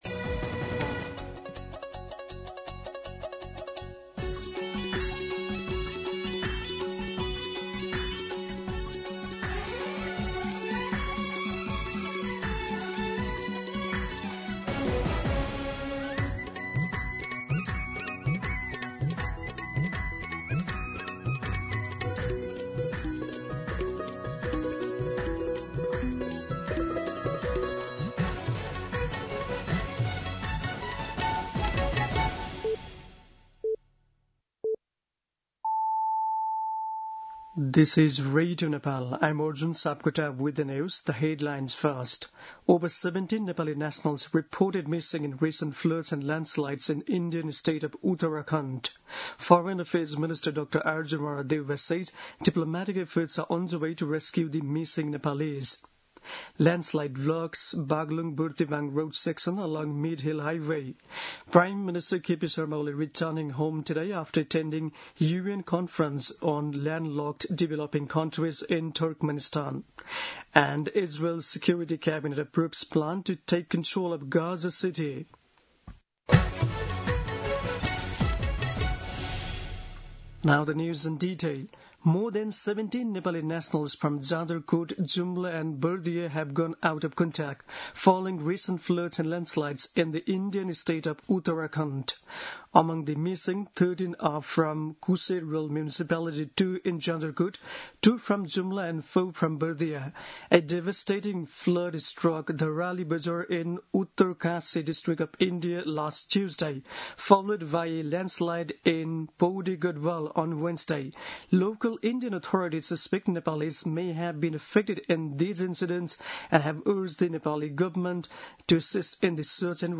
दिउँसो २ बजेको अङ्ग्रेजी समाचार : २३ साउन , २०८२
2-pm-English-News-.mp3